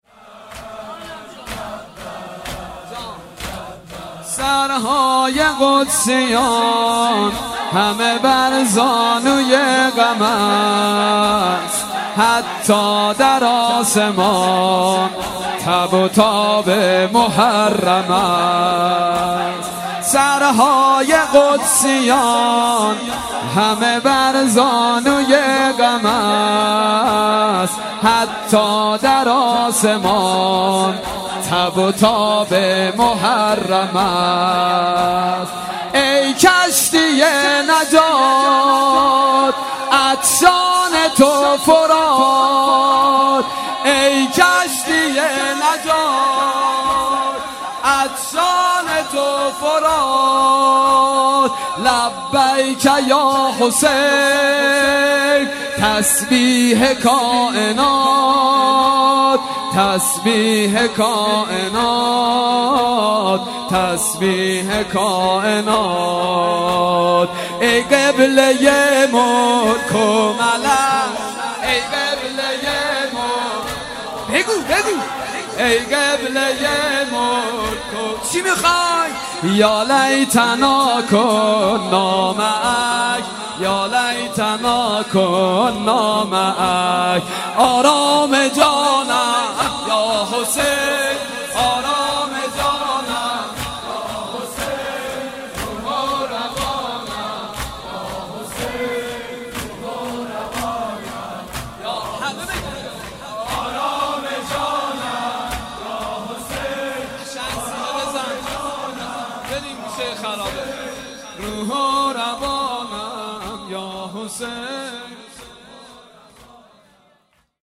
دهه اول محرم الحرام